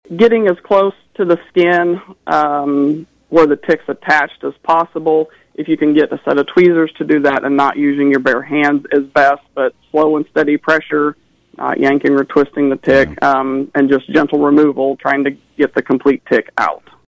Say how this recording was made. KVOE’s Morning Show